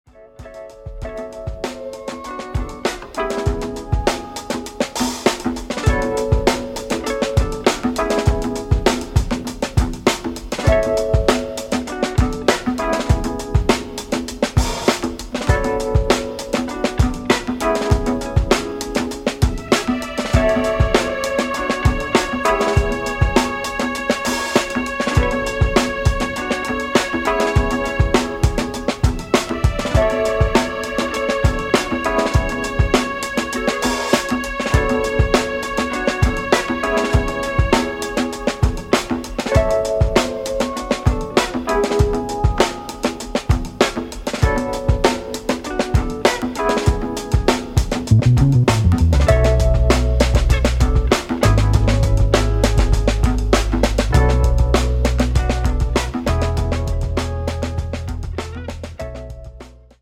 Instrumental funk dons